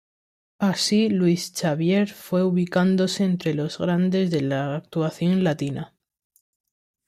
Pronounced as (IPA) /ˈlwis/